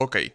voices / voice_acknowledgement / worker_ack1.wav
worker_ack1.wav